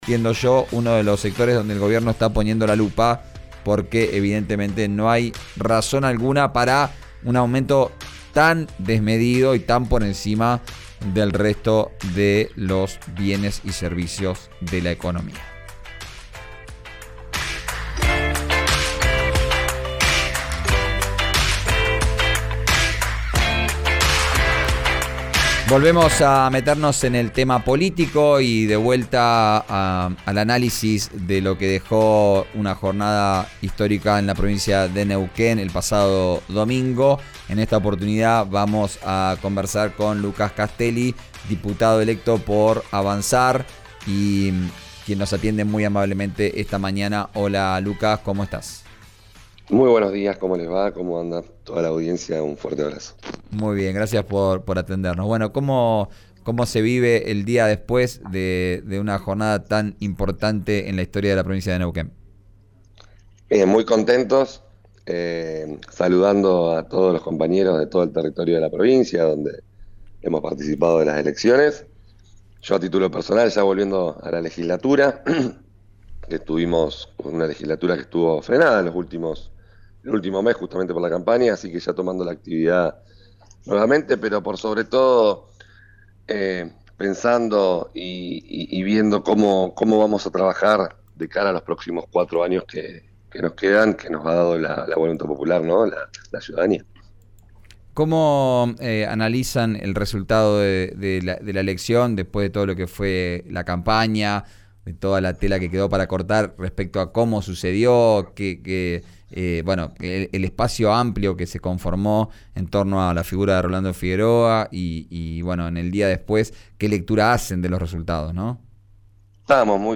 El diputado provincial de Neuquén por "Avanzar" renovó su banca, pero manifestó su interés por formar parte del nuevo gabinete. Escuchá la entrevista en RÍO NEGRO RADIO.
Escuchá al diputado provincial por Avanzar, Lucas Castelli, en «Vos al Aire», por RÍO NEGRO RADIO: